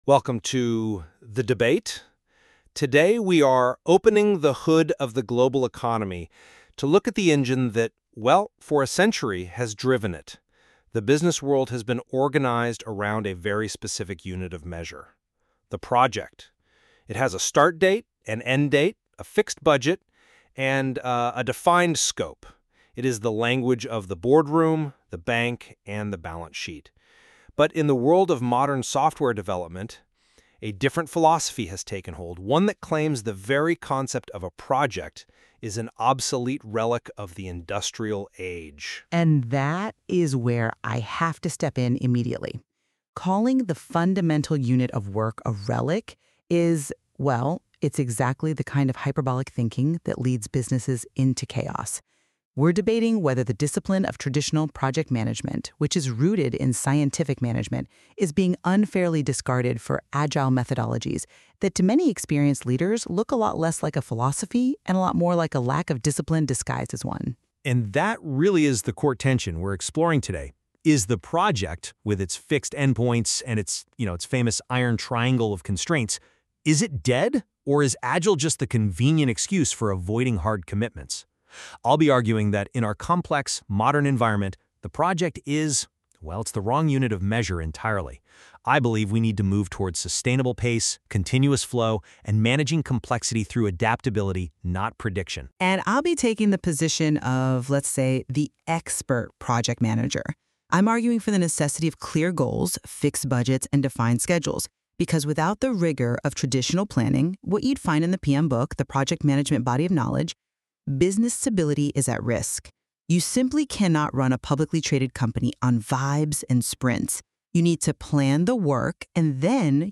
This dialogue explores the fundamental tension between traditional project management and agile methodologies